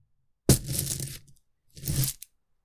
Heavy Money Bag - 1
bag buy coin coins ding drop finance metal sound effect free sound royalty free Sound Effects